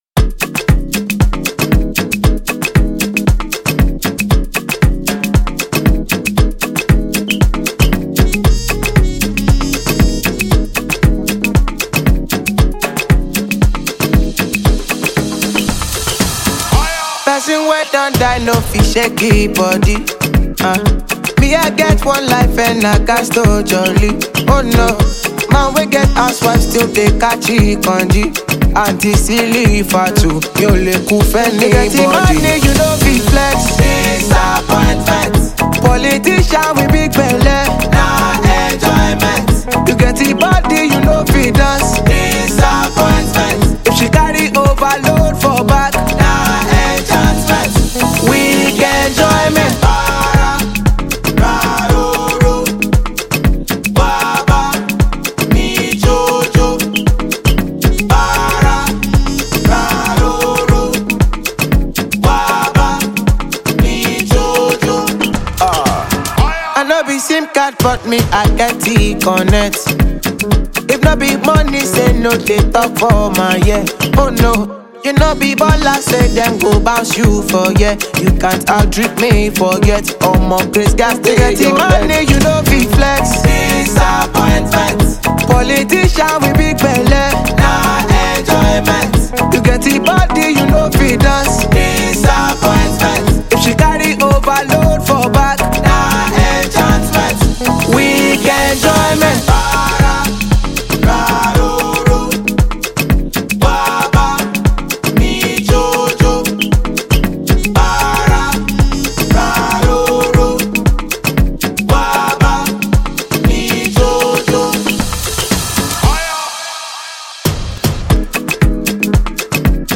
which will keep you on the dance floor this weekend.